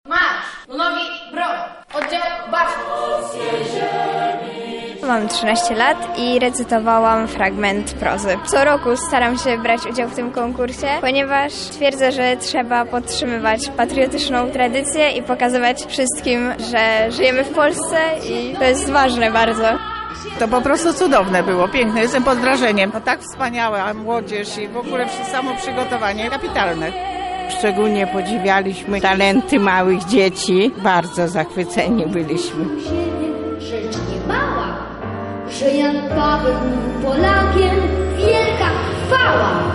98 rocznicę Odzyskania Niepodległości Lublin uczcił koncertem galowym Ojczyzno Moja Najmilsza. Na sali operowej Centrum Spotkania Kultur wystąpili laureaci 8 edycji konkursu Poezji, Prozy i pieśni Patriotycznej.
CSK-koncert-galowy.mp3